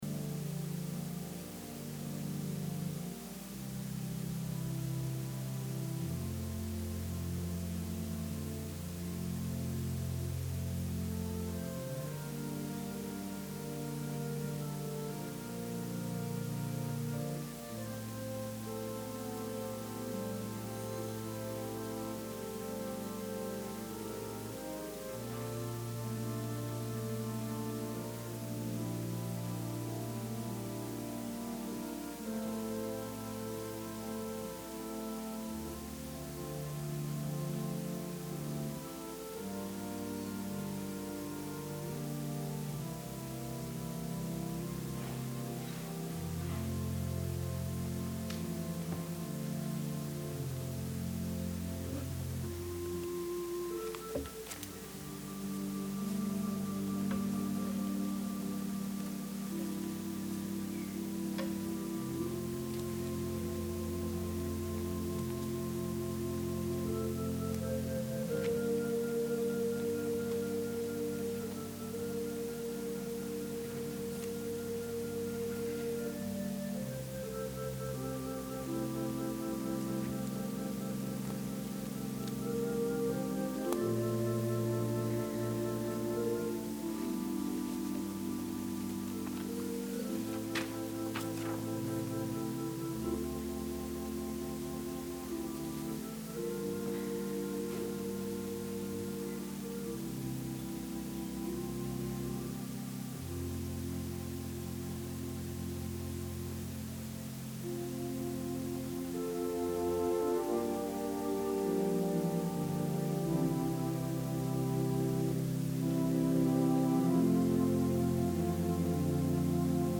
Sermon – October 11, 2020